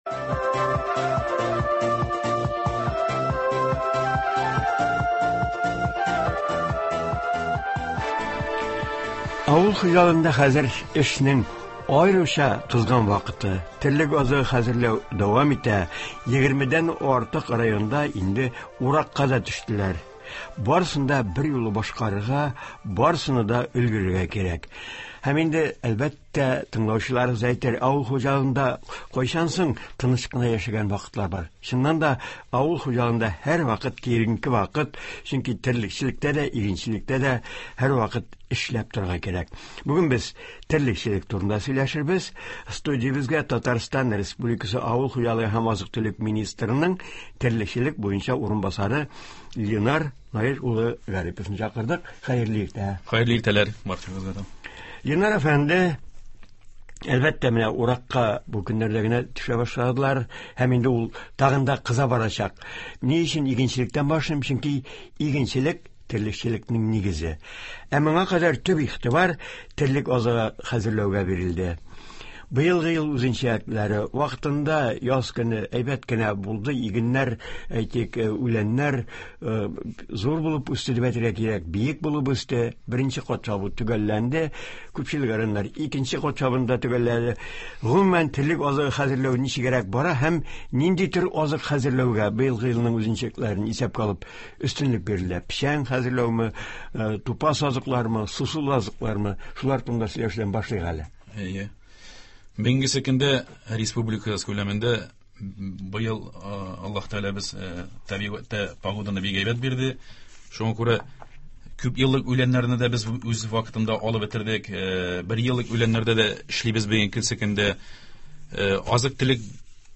Катлаулы табигый шартларда республика терлекчеләре эшне ничек оештыралар, терлек азыгының кайсы төрләренә өстенлек бирелә, бу эшләрдә кайсы районнар алда бара? Болар хакында турыдан-туры эфирда авыл хуҗалыгы һәм азык-төлек министрының терлекчелек буенча урынбасары Ленар Гарипов сөйләячәк, телефон аша килгән сорауларга җавап бирәчәк.